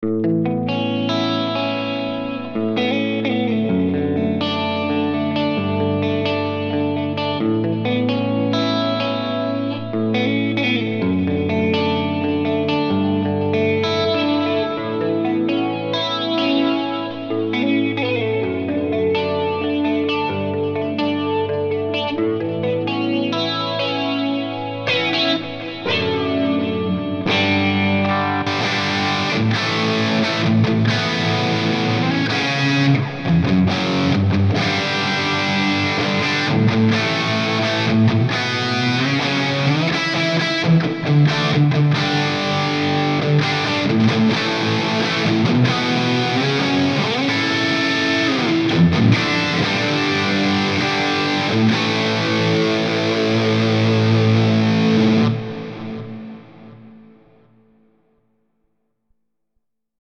This Amp Clone rig pack is made from a Marshall JVM 410H Preamp.
IR USED: MARSHALL 1960A V30 SM57+ E906 POS 1
RAW AUDIO CLIPS ONLY, NO POST-PROCESSING EFFECTS
Hi-Gain